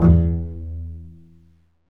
Index of /90_sSampleCDs/Roland LCDP13 String Sections/STR_Cbs FX/STR_Cbs Pizz